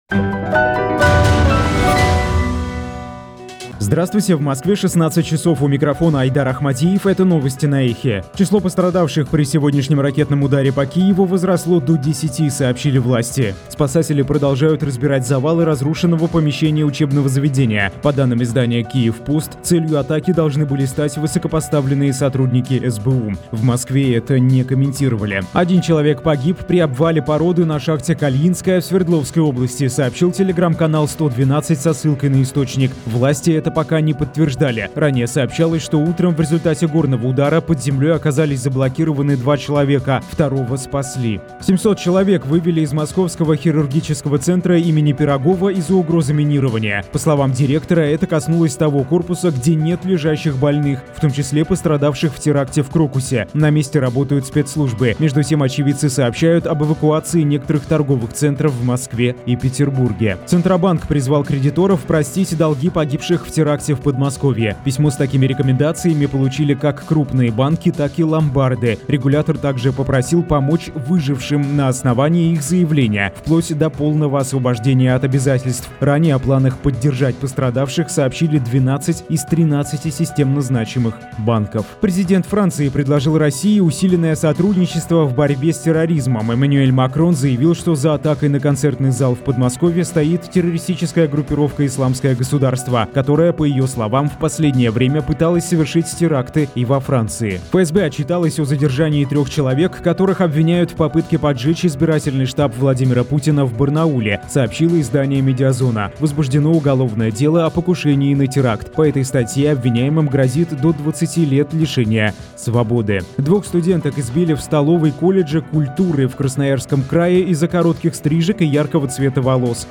Слушайте свежий выпуск новостей «Эха».
Новости